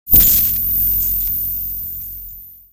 Electrifying Texture, Electric Shock Sound Effect Download | Gfx Sounds
Electrifying-texture-electric-shock.mp3